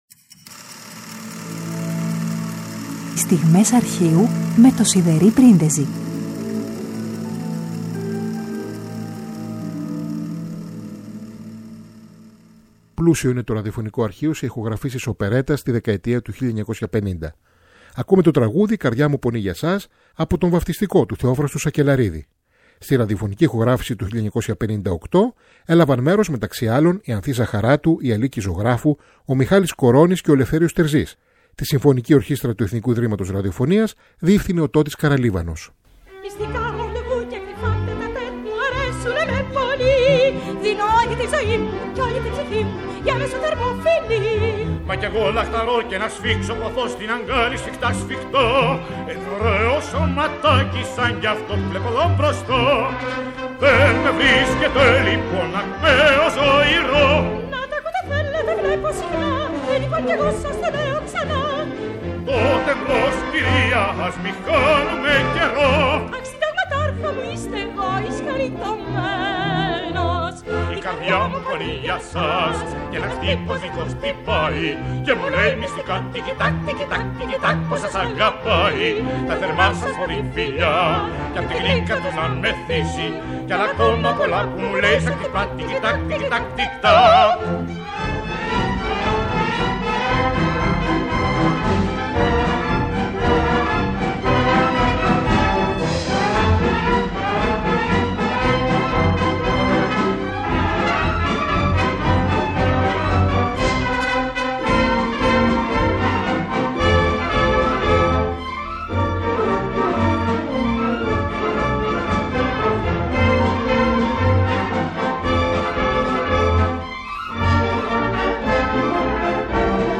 τραγούδι
ραδιοφωνική ηχογράφηση